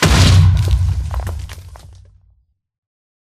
explode2.ogg